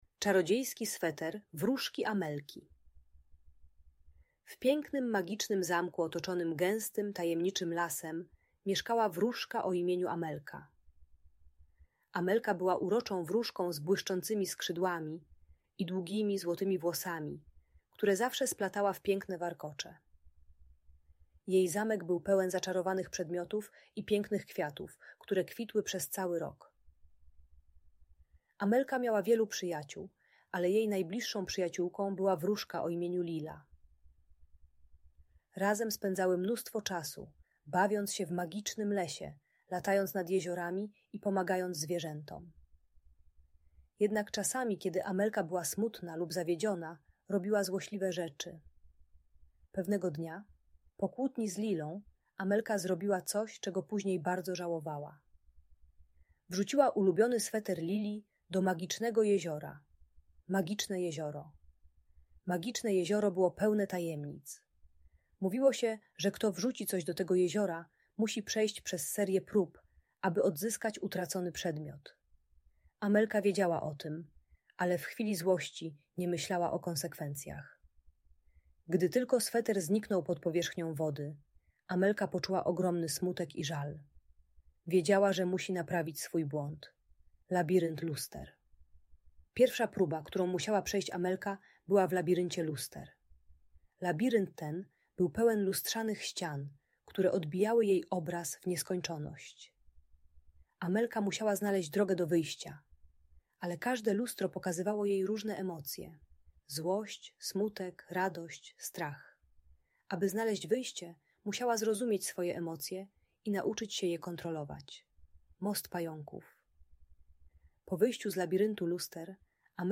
Czarodziejski Sweter Wróżki Amelki - Magiczna Opowieść - Audiobajka